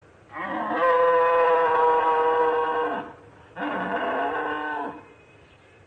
Крик зрелого самца